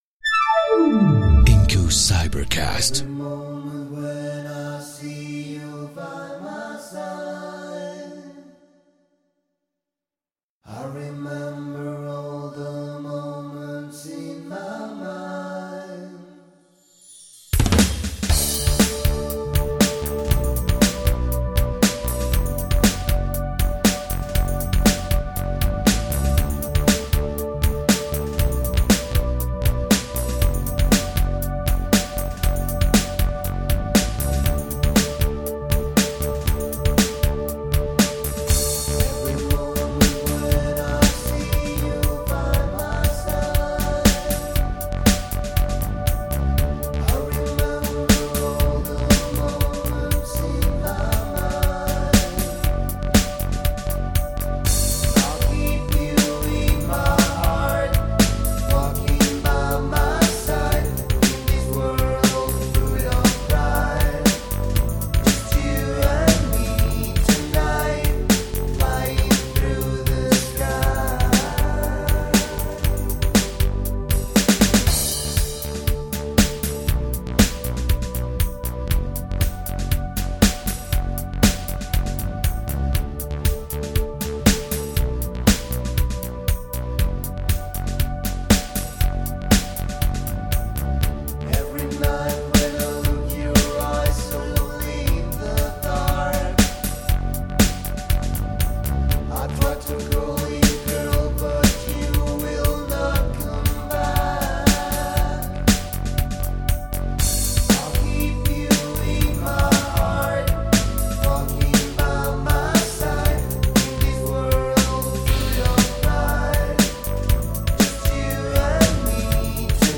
VOZ PRINCIPAL & COROS.
SECUENCIAS, SAMPLEOS, PERCUCIONES, TECLADOS, BAJO & LETRAS.